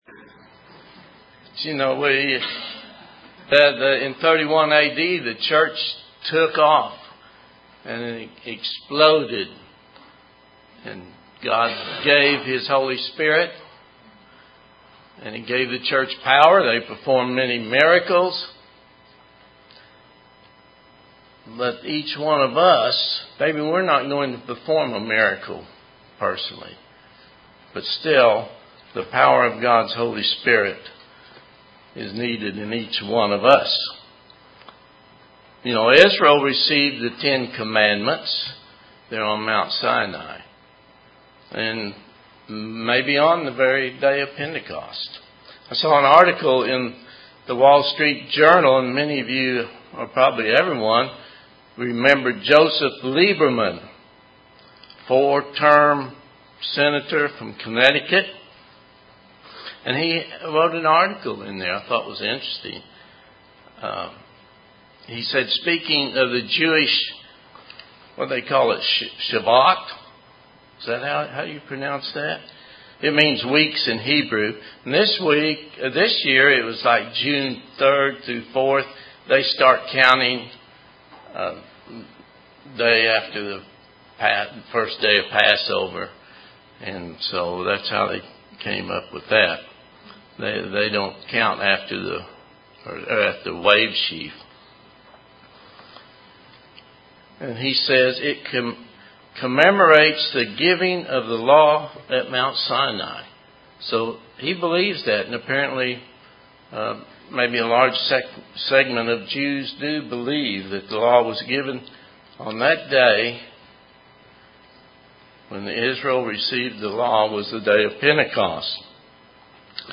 This sermon was given on Pentecost.
Given in Oklahoma City, OK